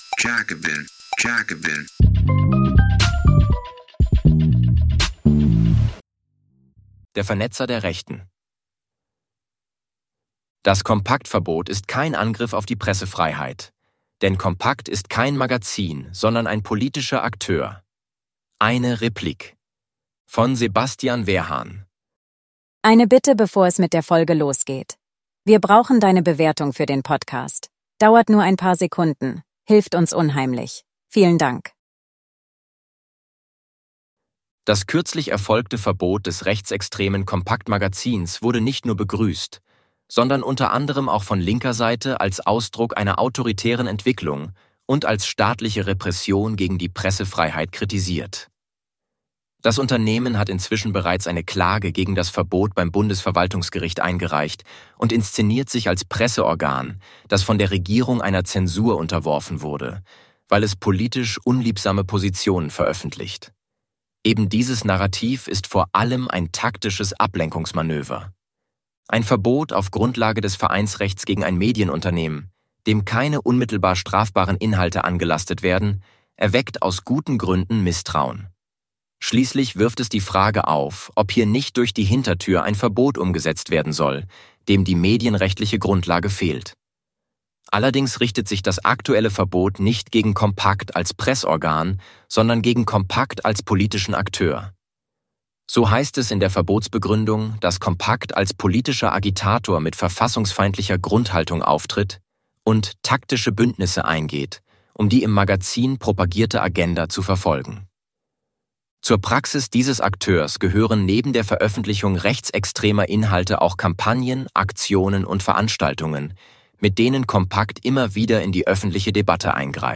Interview vom 03.